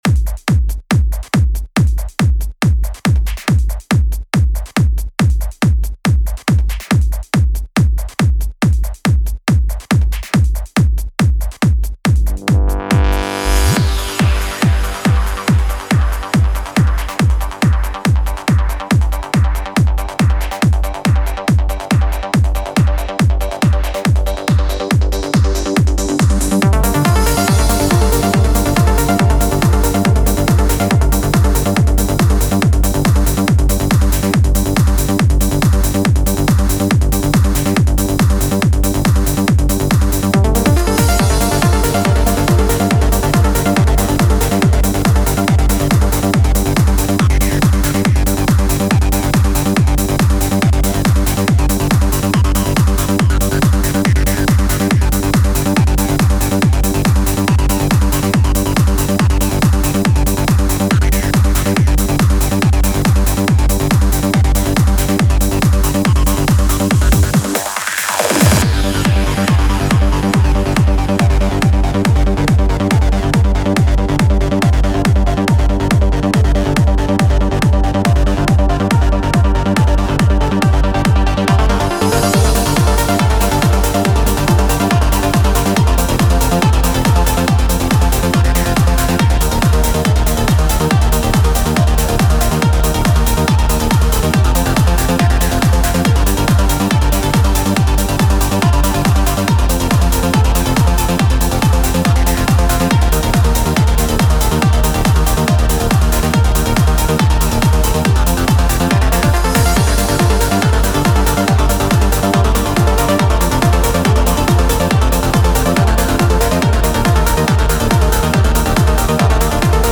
Жанр:Trance